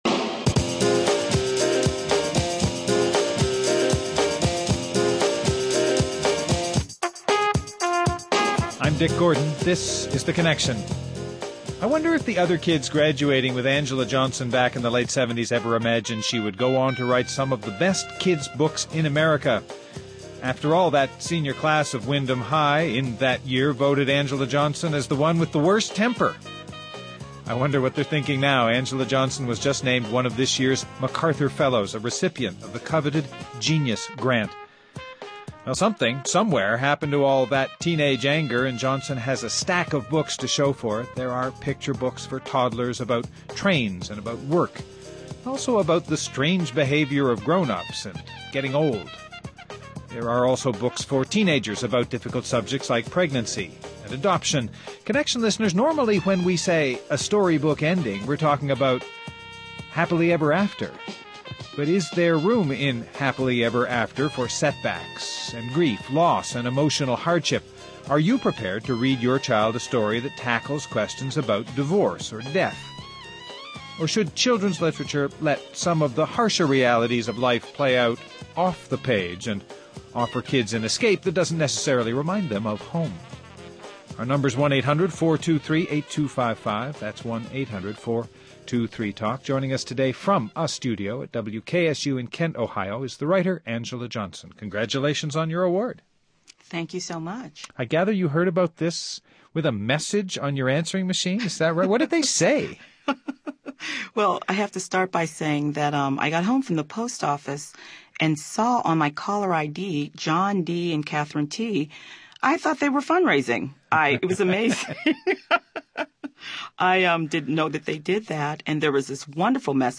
Guests: Angela Johnson, 2003 MacArthur Fellow and author of, most recently, “The First Part Last” and “I Dream of Trains”